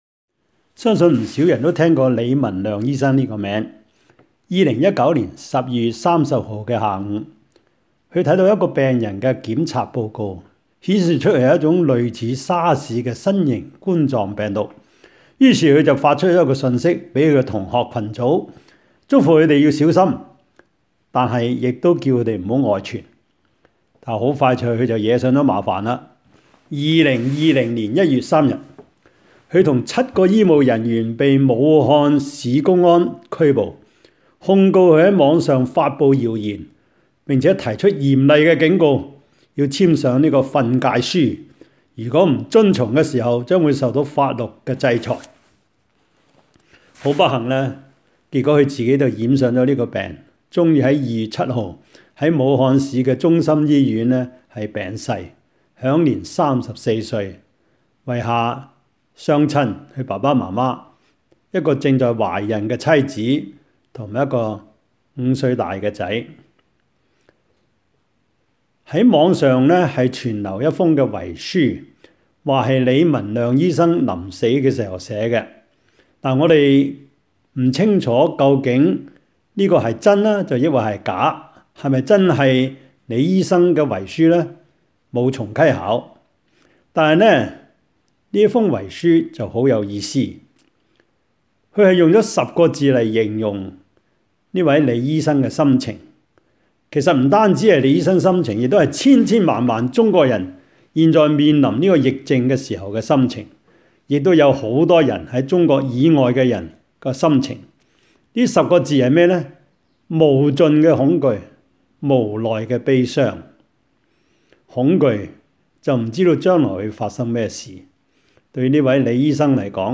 Service Type: 主日崇拜
Topics: 主日證道 « 相見好同住難 Anger 1 »